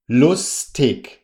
ลุส-ทิก(ช)